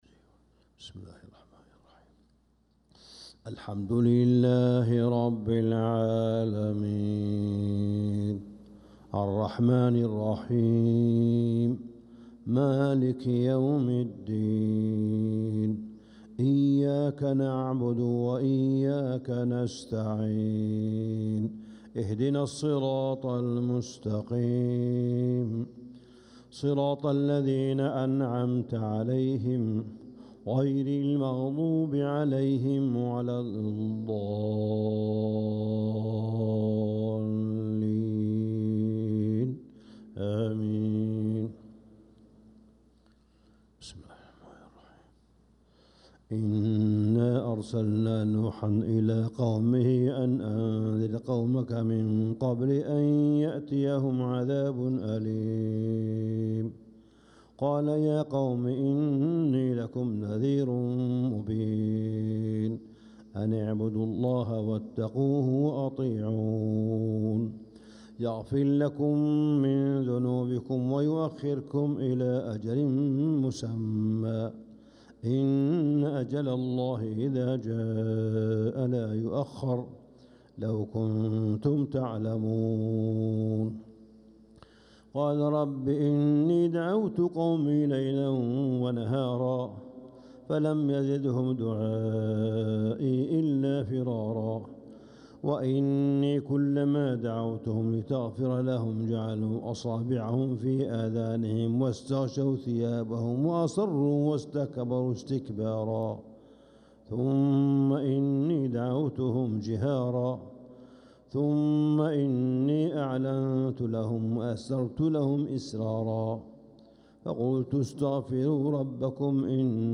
صلاة الفجر للقارئ صالح بن حميد 5 ربيع الأول 1446 هـ
تِلَاوَات الْحَرَمَيْن .